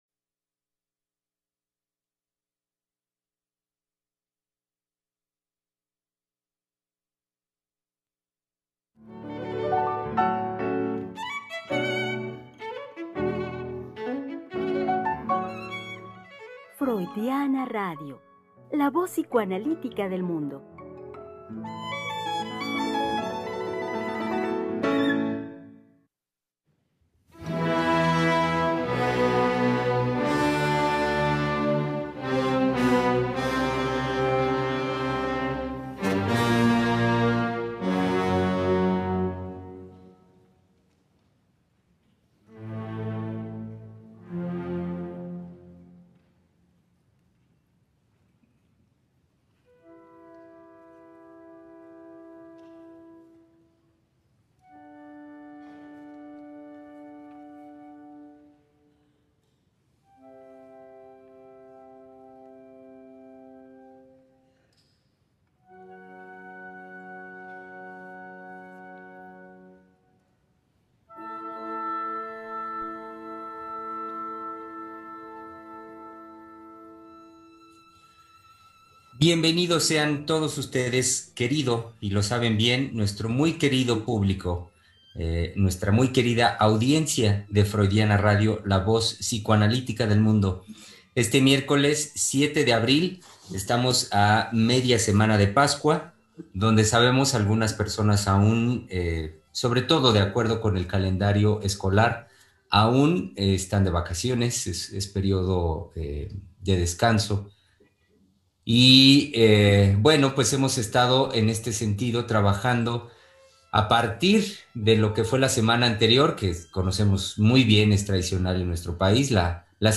Conversación con las psicoanalistas